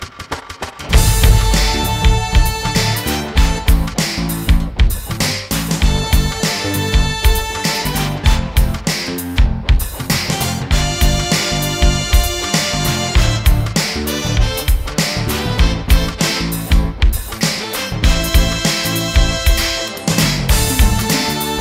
Muzyka potrzebna do odgadnięcia quizu
quiz-muzyka-do-rozpoznania.mp3